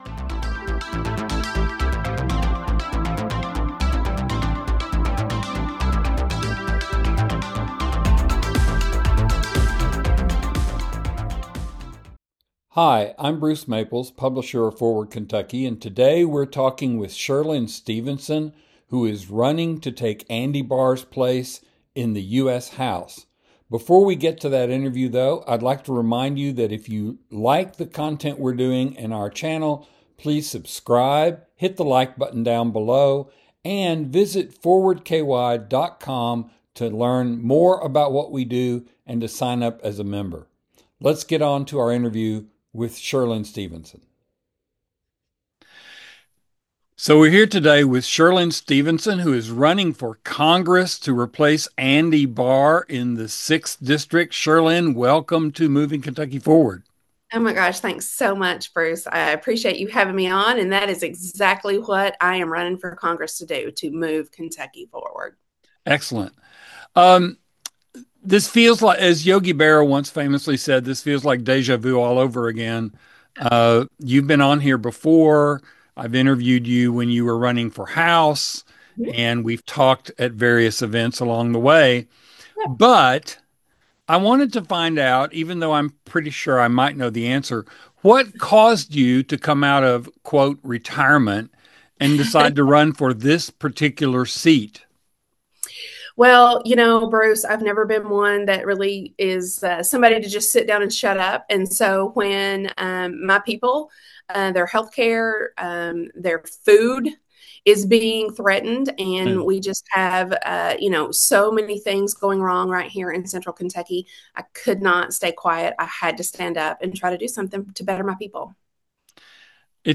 An interview with Cherlynn Stevenson